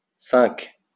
wymowa:
IPA[sɛ̃k] (zob. uwagi) ?/i